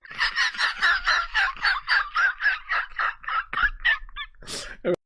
Risa Ahogada Sound Button - Free Download & Play